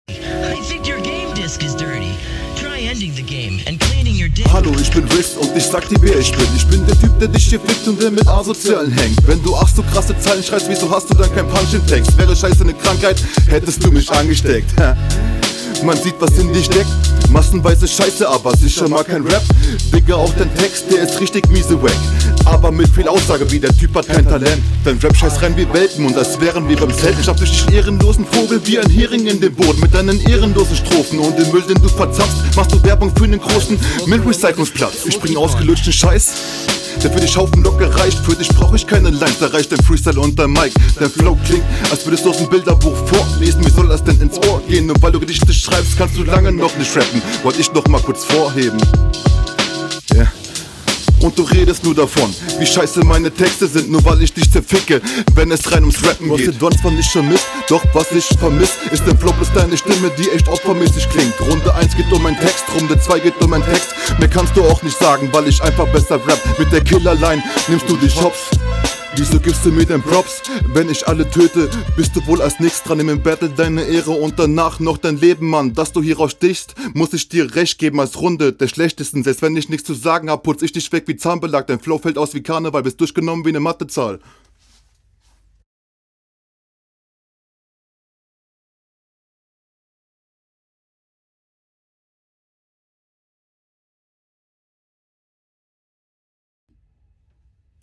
flowlich finde ich dich besser, manchmal aber etwas unverständlich und verhaspelt, versuch die Wörter entweder …
Stimmeinsatz wie immer top, klingt sehr gut auf dem Beat.